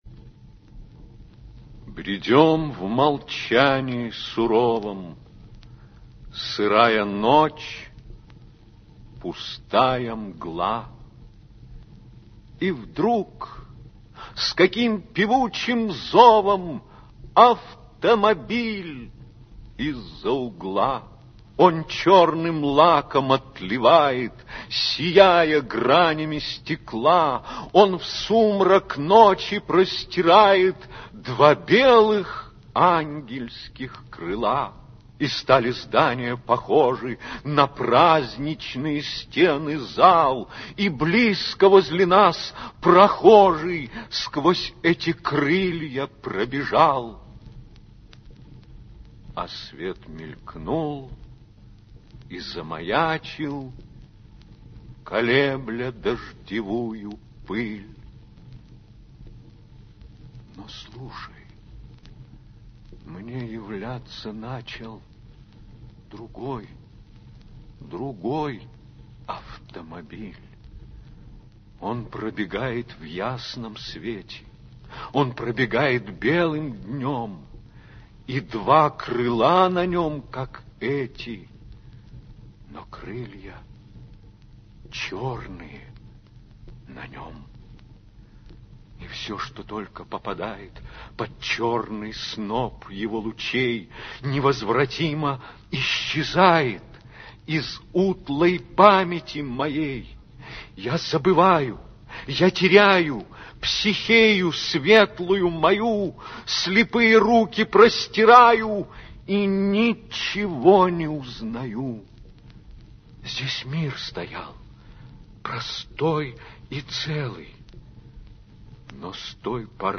4. «Ходасевич Владислав – Автомобиль (читает Валерий Ивченко)» /
hodasevich-vladislav-avtomobil-chitaet-valerij-ivchenko